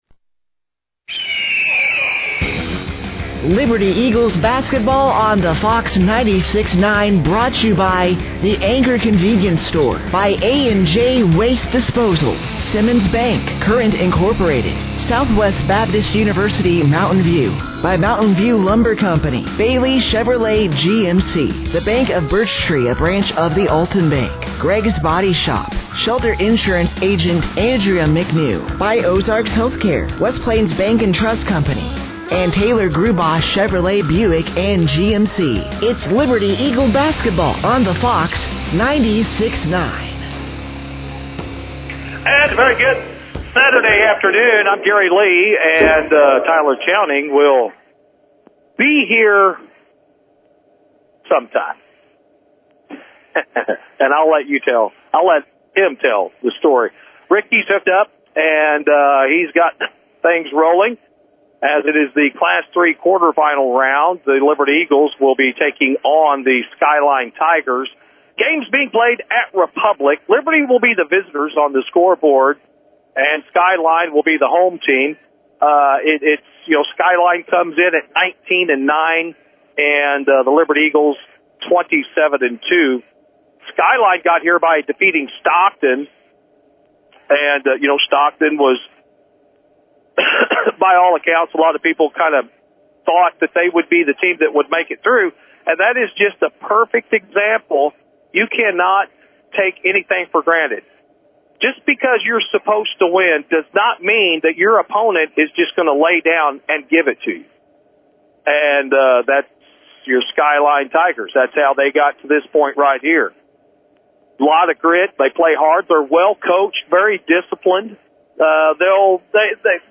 The Liberty Eagles Boys Basketball team traveled to Republic High School, site of the Class 3 Sectionals for a Semi Final Matchup over The 19-9 Skyline Tigers on Saturday, March 7th, 2026.
Liberty-Eagles-vs.-Skyline-Tigers-3-7-26-Class-3-Sectionals-.mp3